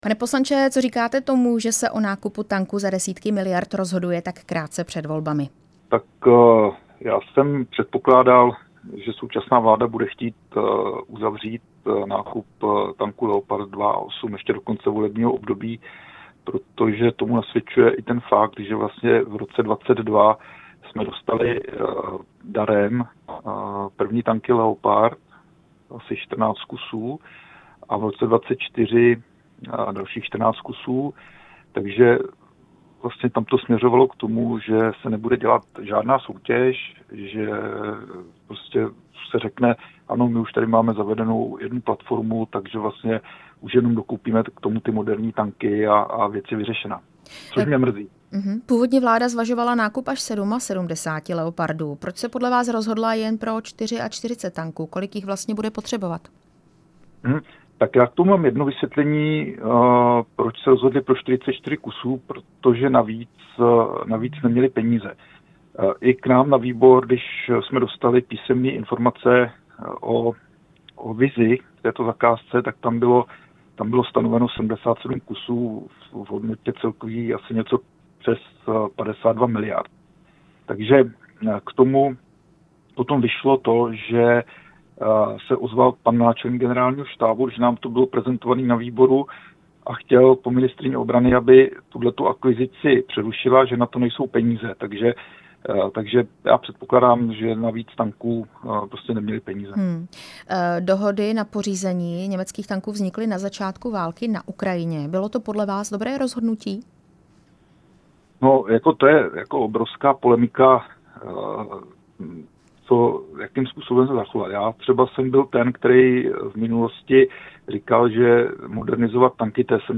Rozhovor s místopředsedou výboru pro obranu, poslancem Pavlem Růžičkou z hnutí ANO